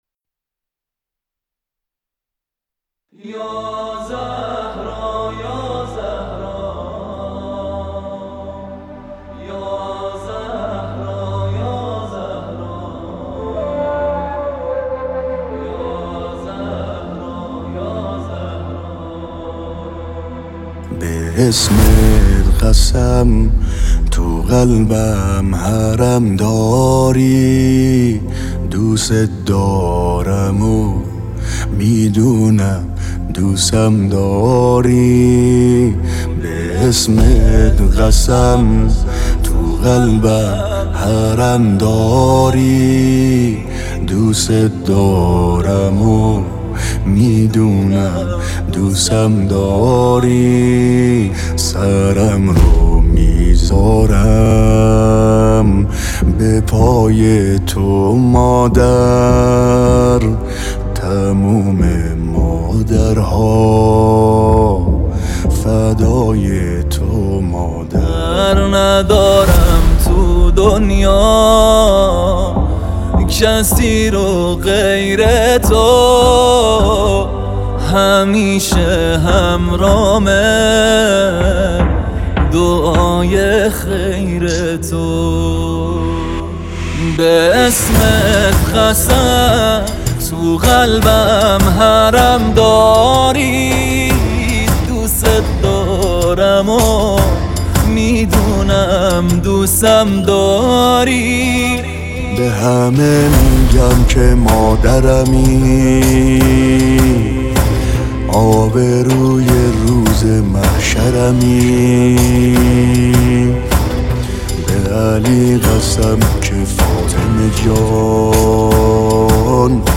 نوحه